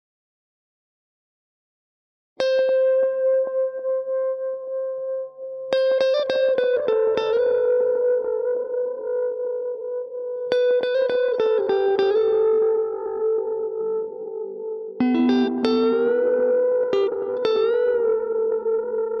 夏末吉他2
描述：吉他
标签： 100 bpm Acoustic Loops Guitar Electric Loops 1.62 MB wav Key : A
声道单声道